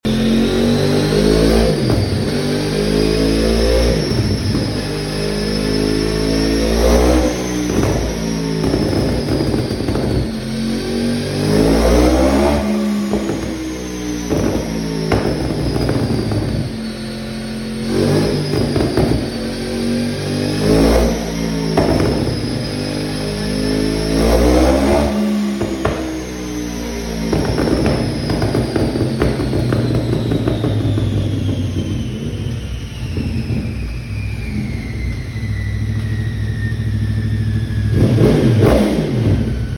Yamaha R1 BIG BANG🔥🔥 Akrapovic sound effects free download
Yamaha R1 BIG BANG🔥🔥 Akrapovic exhaust system! In some Pops and flame action after the ECU flash! That crossplane crankshaft sound, always fantastic 👌🏼